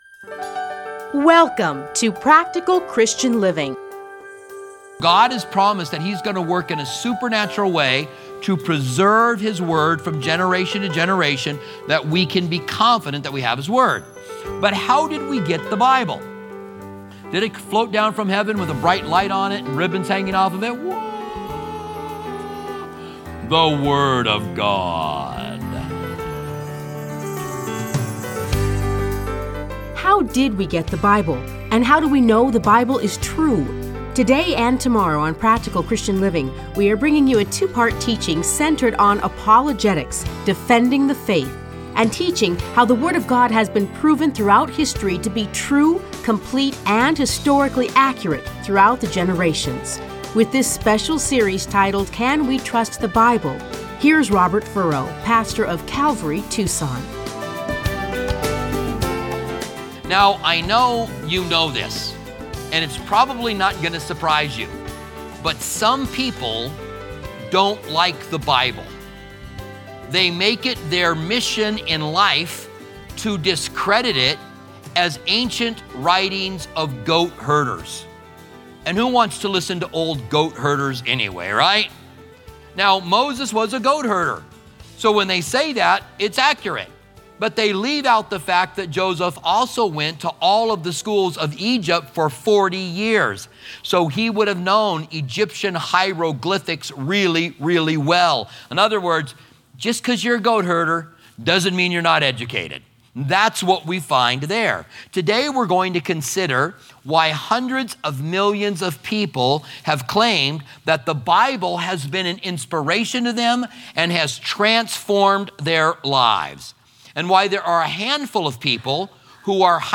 Listen to a teaching from Various Scriptures.